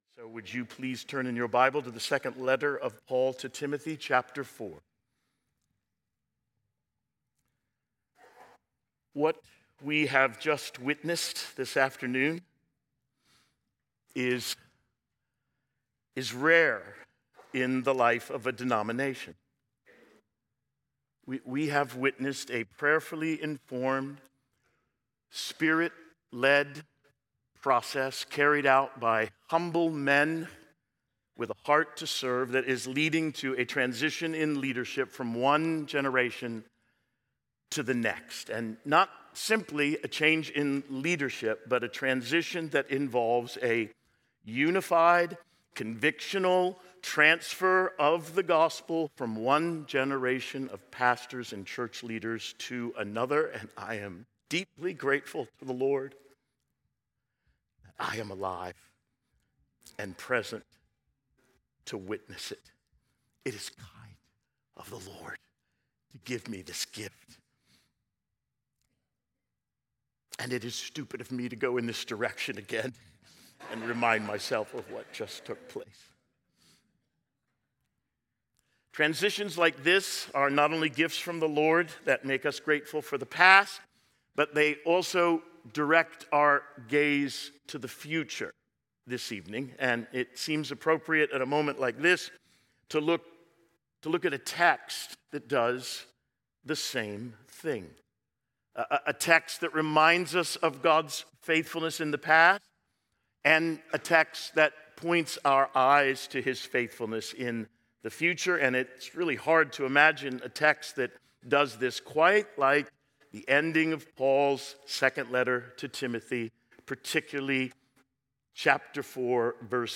Sermon
2025 Pastors Conference